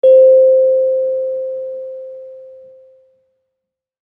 kalimba1_circleskin-C4-pp.wav